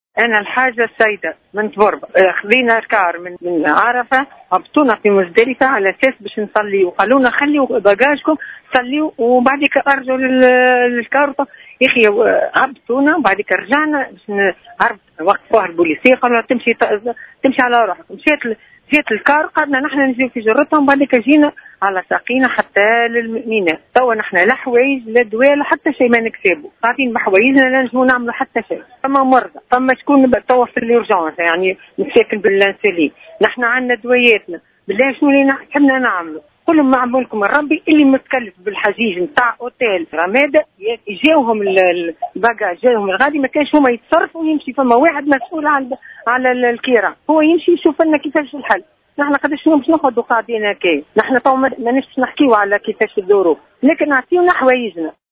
حاجّة تتوجه بنداء استغاثة